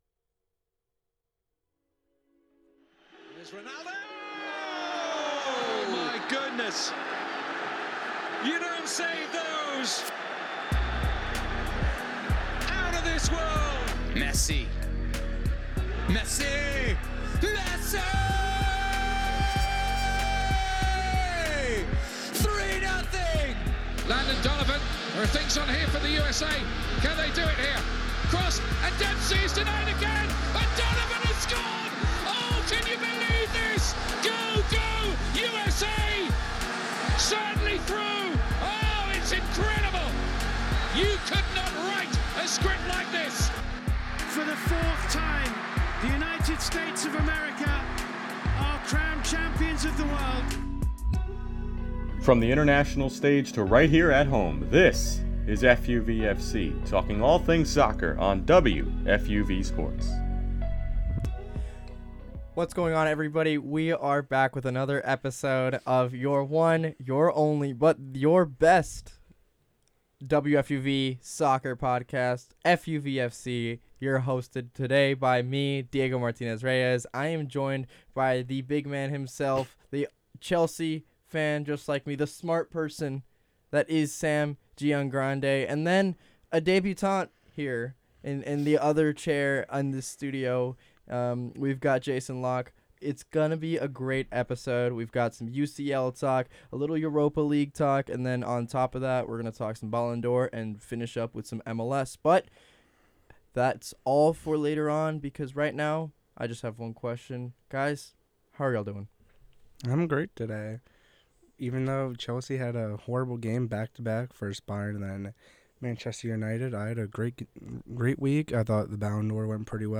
From the MLS to the Premier League, La Liga and the international stage, WFUV Sports' FUV FC features conversation on all the biggest stories in the soccer world.